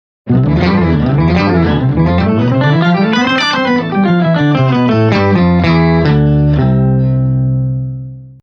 The EHX Nano POG can simultaneously generate multiple octaves from your input signal.
With the Nano POG, you can mix together your dry signal with two different octaves (one above and one below your original note) to create totally new, inspiring tones.
Incredible Tracking
Nano-POGPolyphonic-Octave-Generator-Incredible-Tracking.mp3